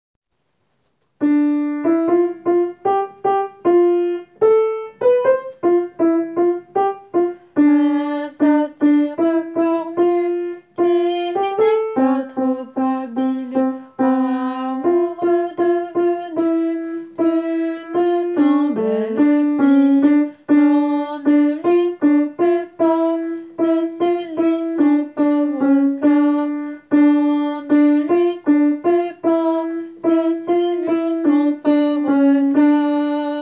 Alto :
satire-alto.wav